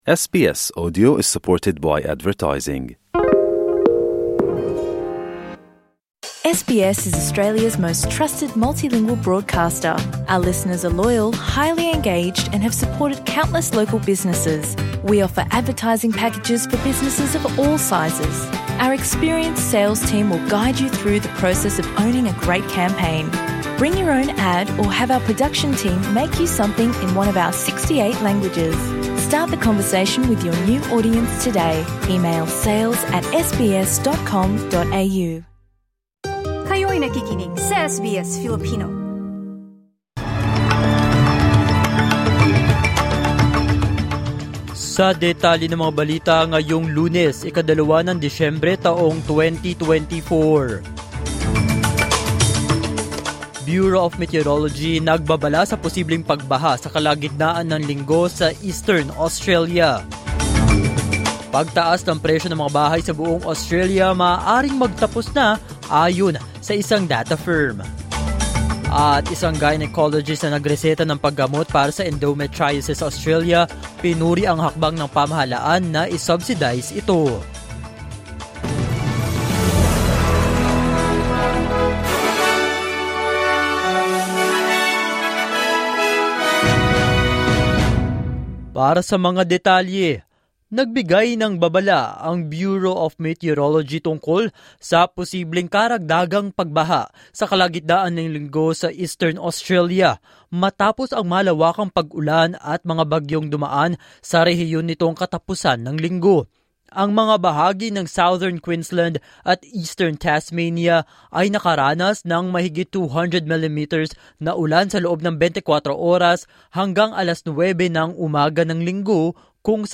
SBS News in Filipino, Monday 2 December 2024